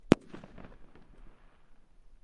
爆炸 " 005 烟花
Tag: 大声 臂架 轰隆 烟花 爆竹 爆炸